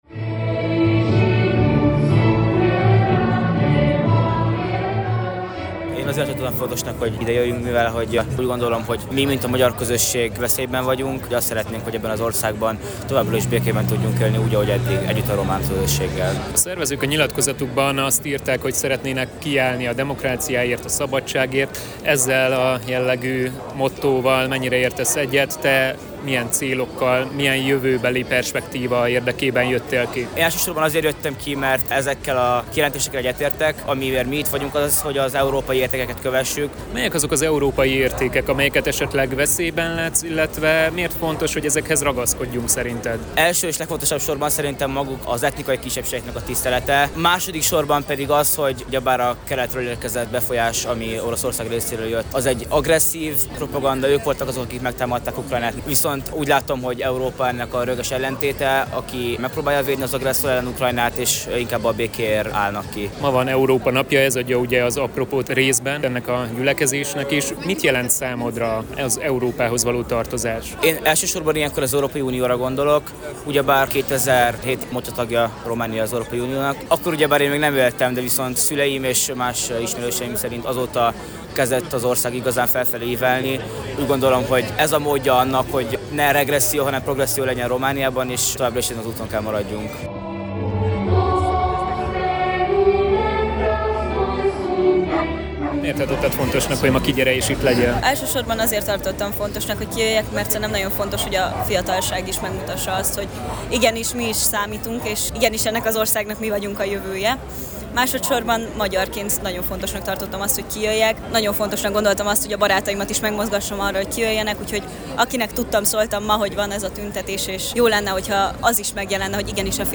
Megmozdulást tartottak több nagyvárosban, így Kolozsváron is az Európa-napon.
A demonstráción csaknem ezer fős, főleg fiatalokból álló tömeg gyűlt össze.